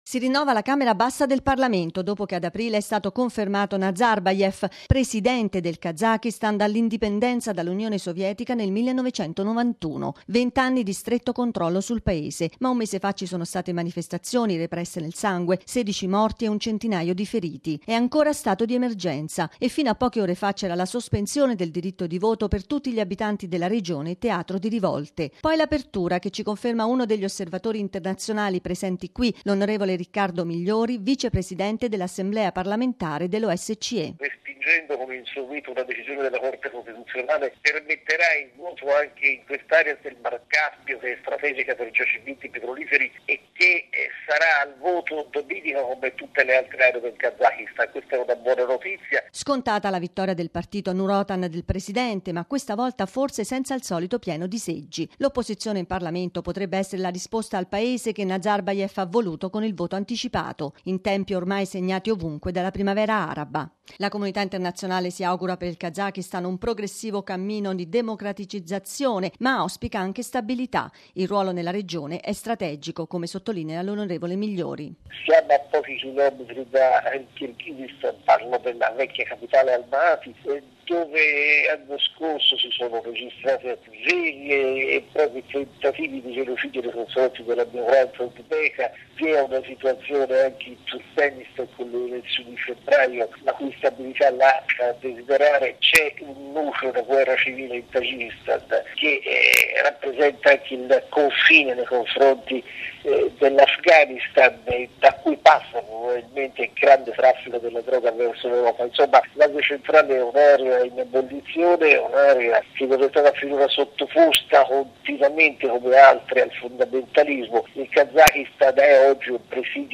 Il servizio